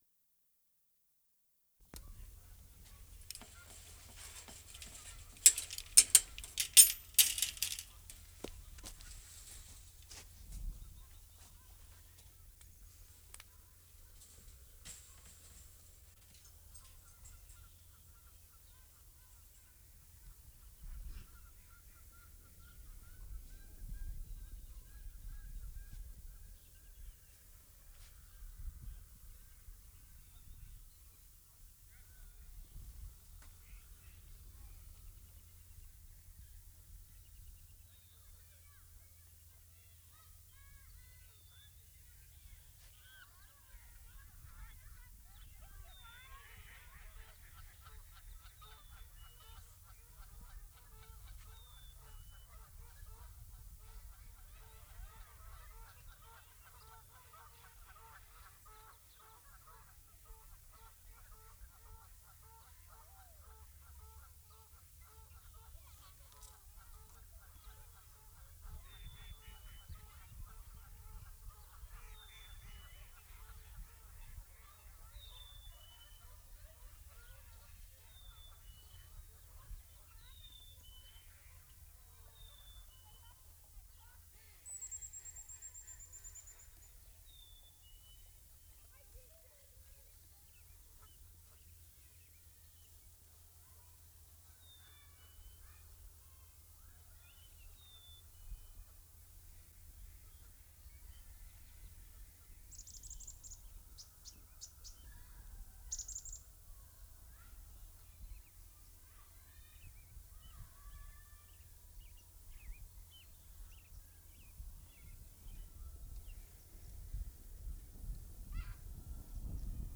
RESTAURANT / G. REIFEL BIRD SANCTUARY March 20, 1973
BIRD SANCTUARY 2'50"
3. Opens with gate opening, geese and other water birds in the background. Children's voices. Lots of wind on microphones.